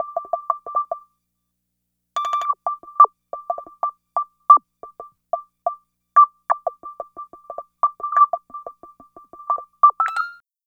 Synth 06.wav